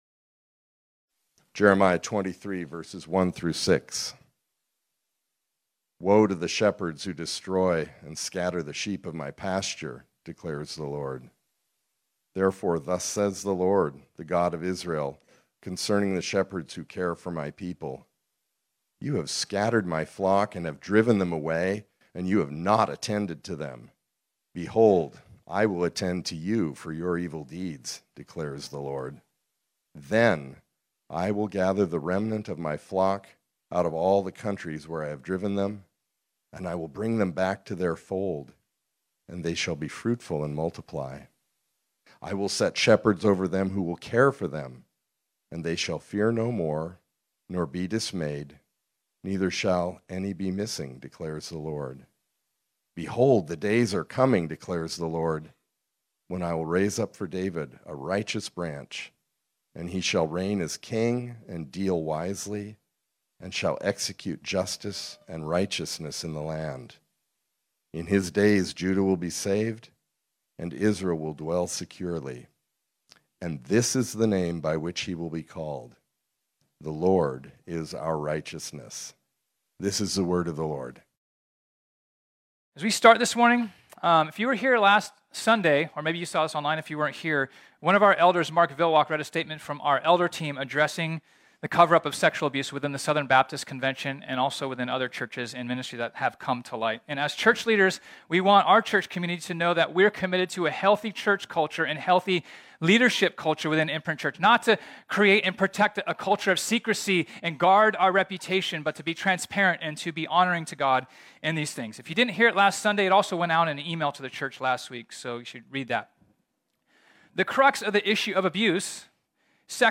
This sermon was originally preached on Sunday, June 12, 2022.